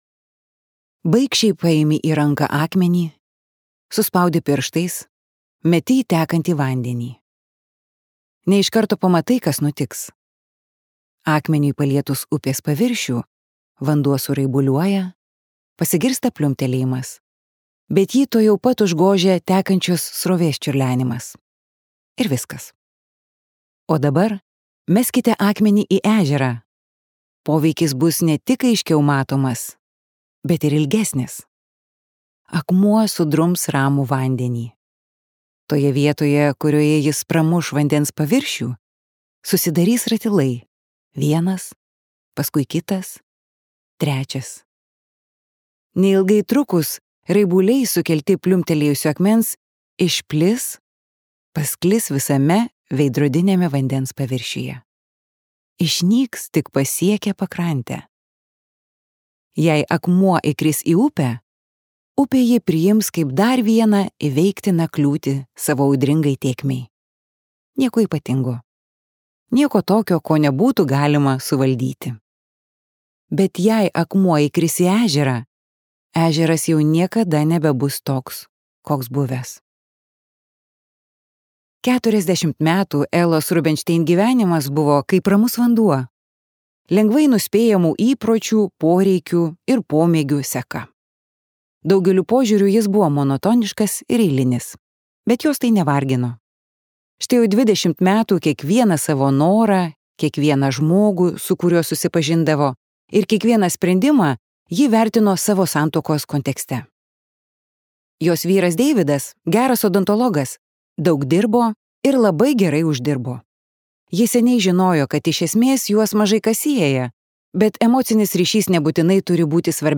Keturiasdešimt meilės taisyklių | Audioknygos | baltos lankos